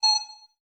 Holographic UI Sounds 58.wav